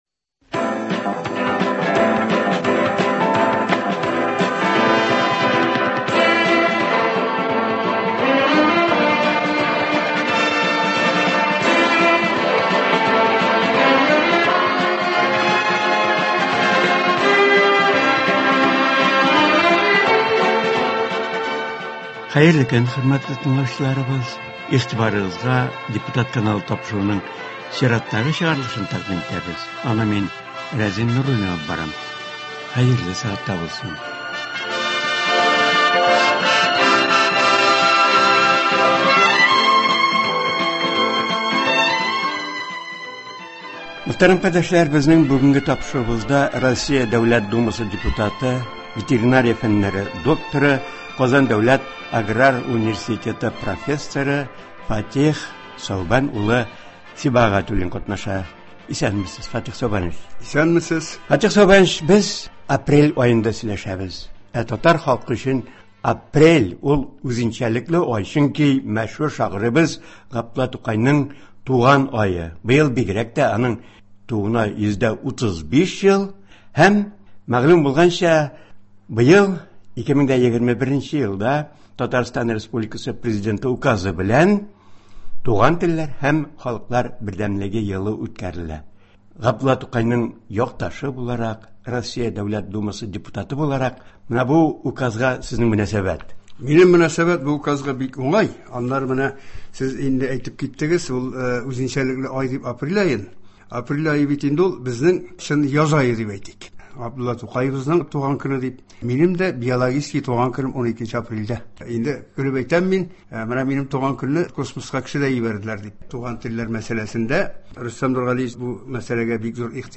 Болар хакында Россия Дәүләт Думасы депутаты, Казан дәүләт аграр университеты профессоры Фатыйх Сәүбән улы Сибгатуллин сөйли.